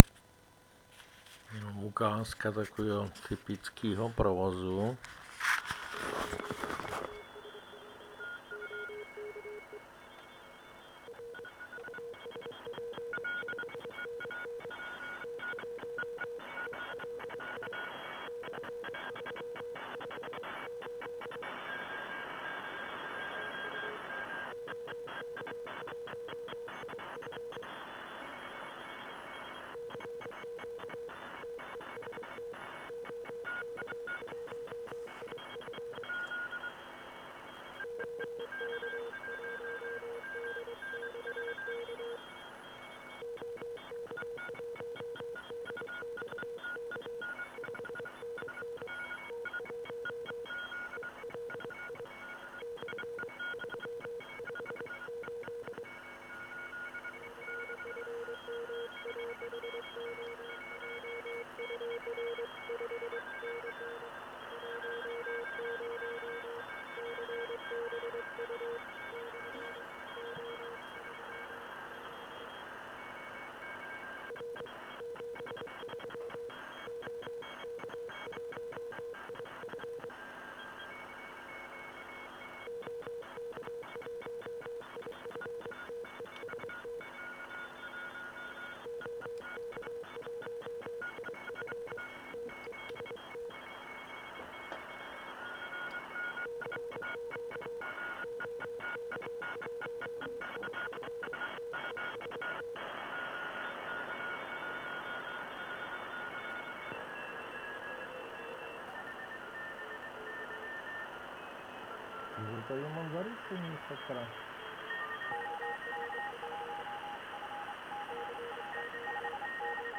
A teď to porovnejte (a to zrovna v té citlivé části), kde Hejkal poráží PIXIDA 612.
Kus zavodu.mp3